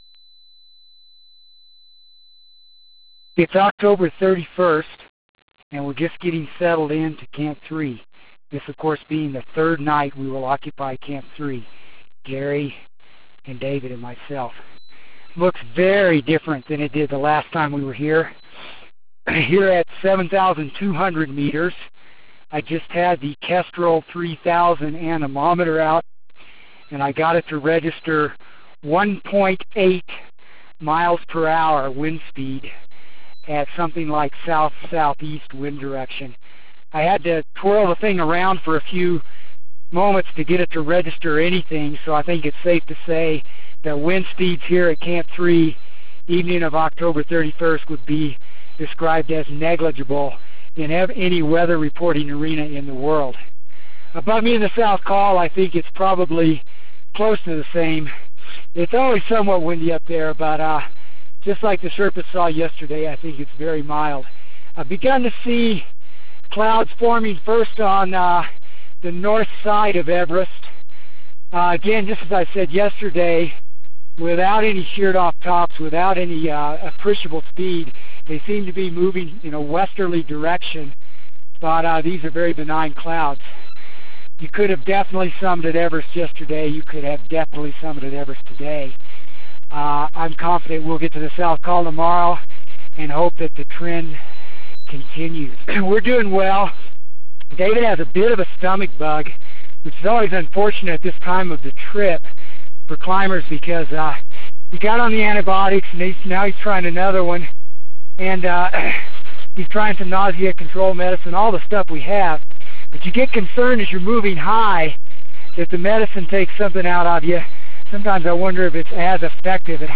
October 31 – Team can smell a summit from Camp 3